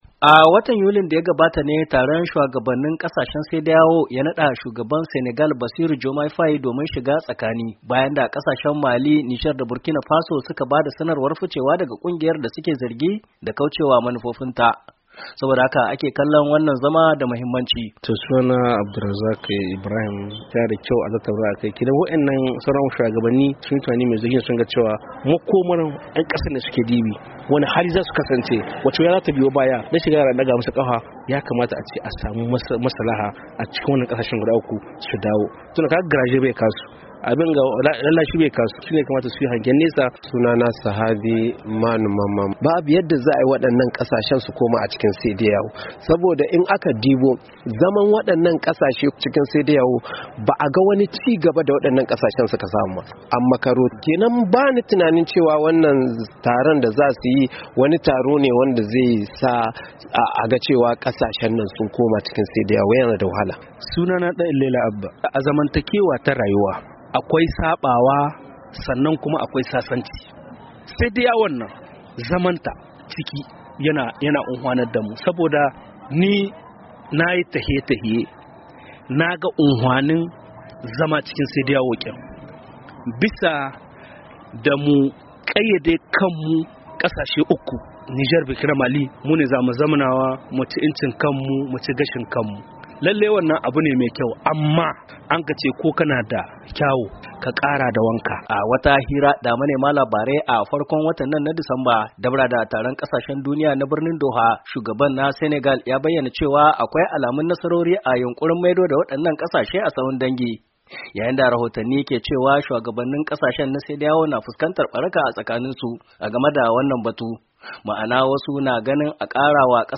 A saurarrin rahoton